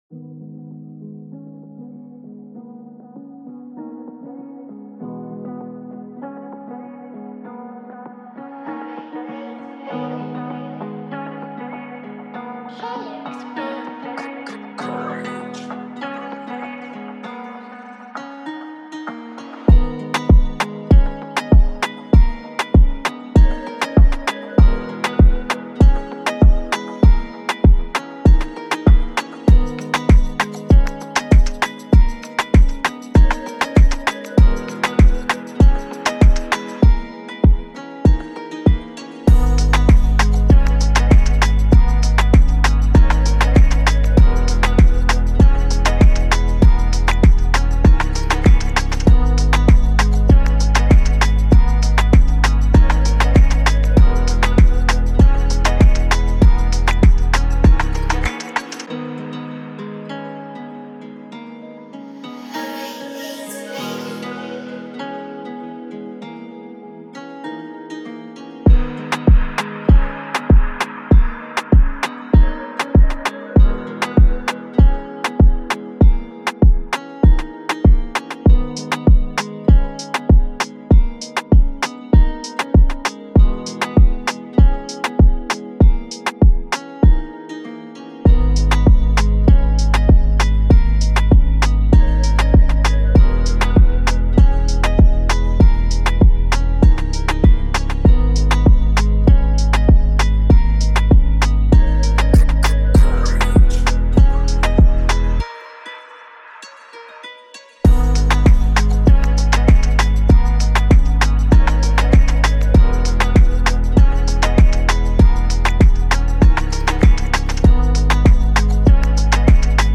98Bpm G#min